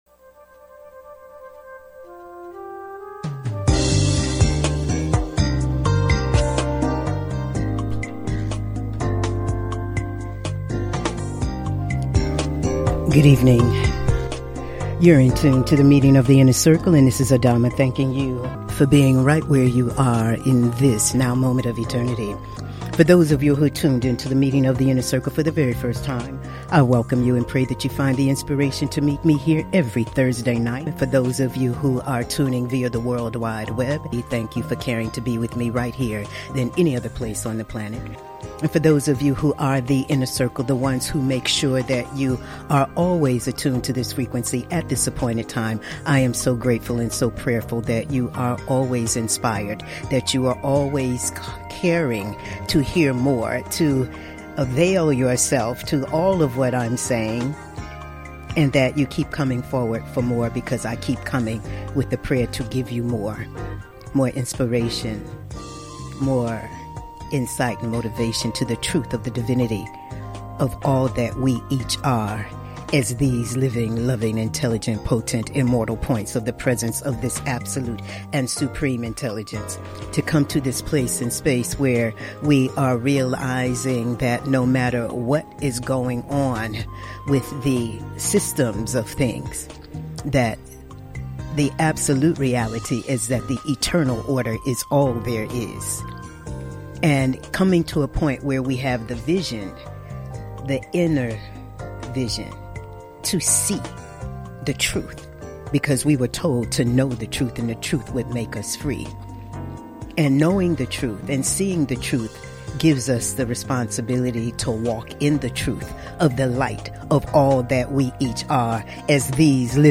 Monologues
Talk Show